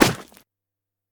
PixelPerfectionCE/assets/minecraft/sounds/item/shield/block5.ogg at ca8d4aeecf25d6a4cc299228cb4a1ef6ff41196e